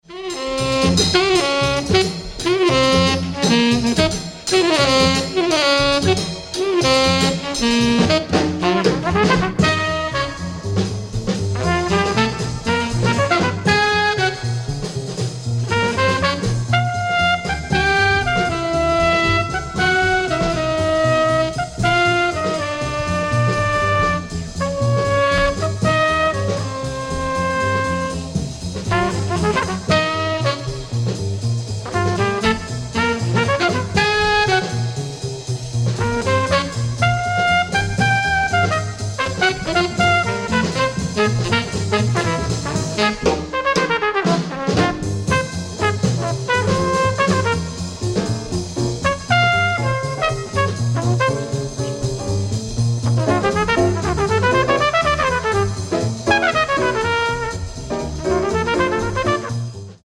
epic blues line